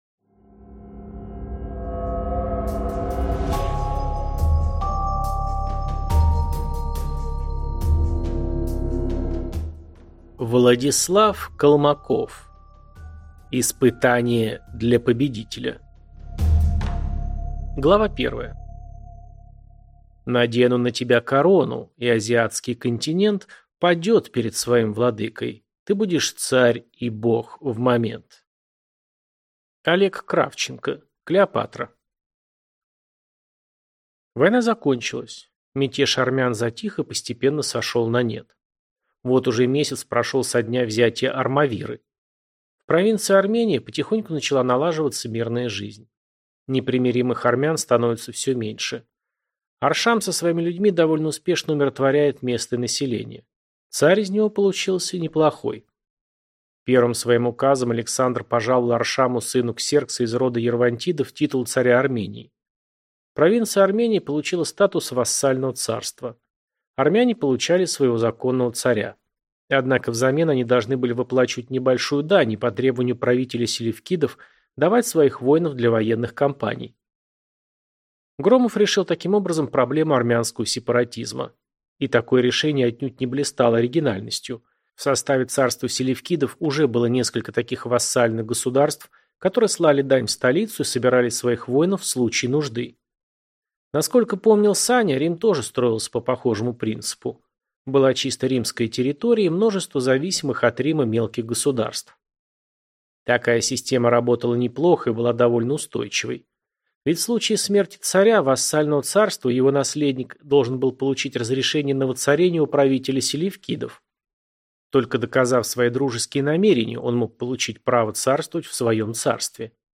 Аудиокнига Испытание для победителя | Библиотека аудиокниг